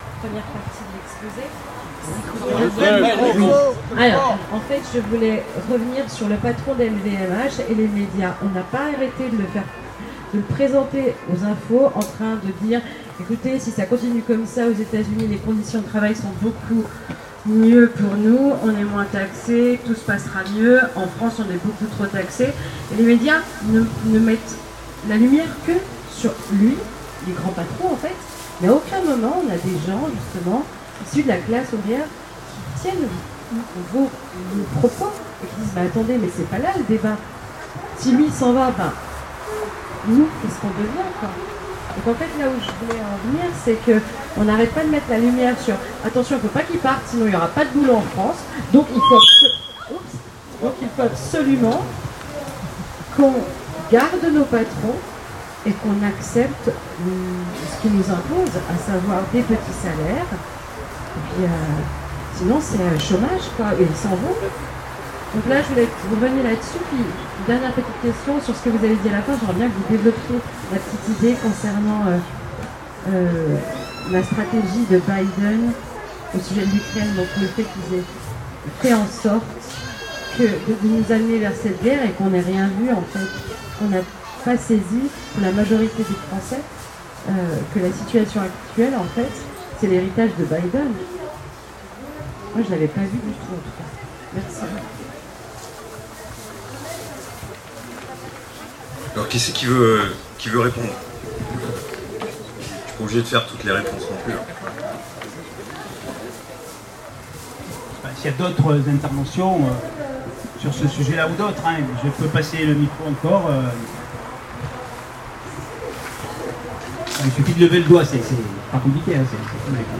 Fête LO 2025 à Marseille : Débat